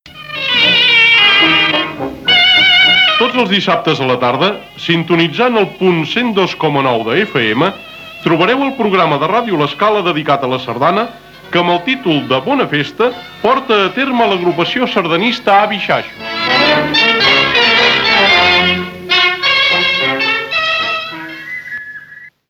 Presentació del programa
Musical